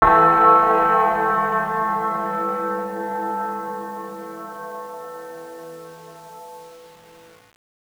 Church Bell.wav